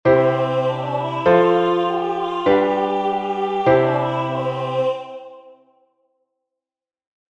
Partitura voz e piano con acordes